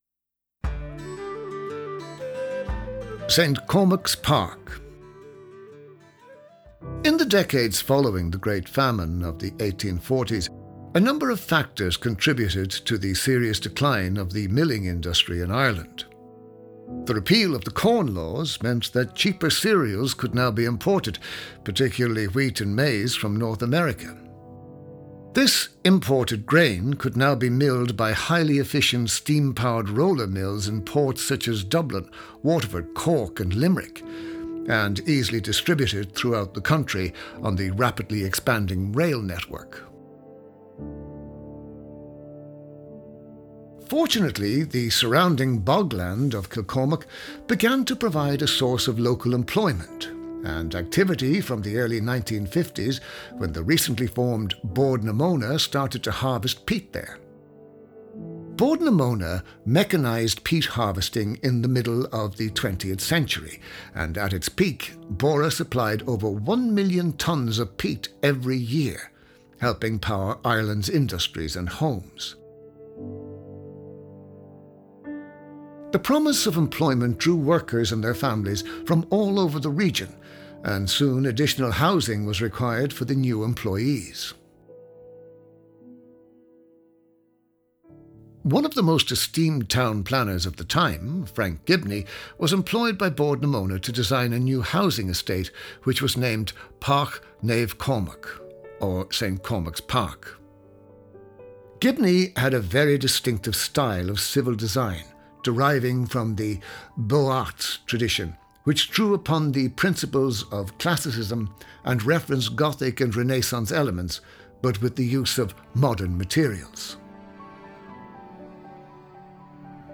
The Kilcormac Audio Guide will introduce you to the history and heritage of the town. We will also meet members of the community who have made this charming place their home.
Throughout the guide, you will hear local people narrating stories from the past.